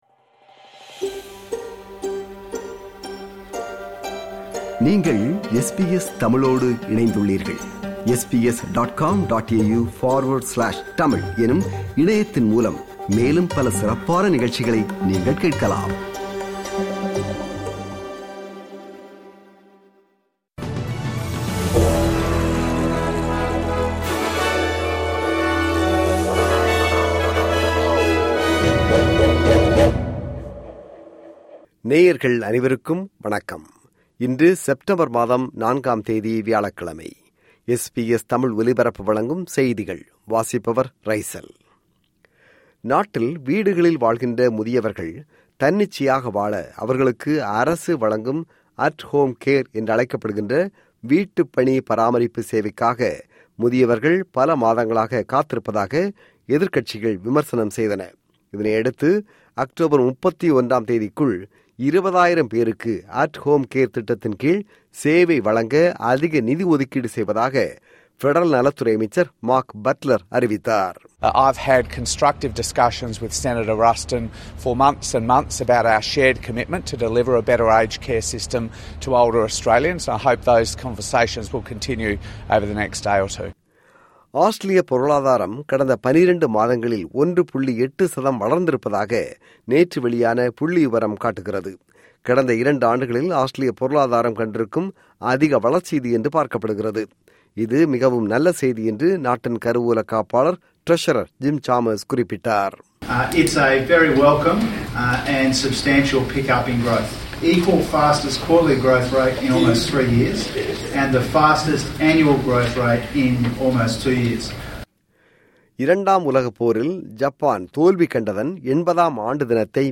SBS தமிழ் ஒலிபரப்பின் இன்றைய (வியாழக்கிழமை 4/09/2025) செய்திகள்.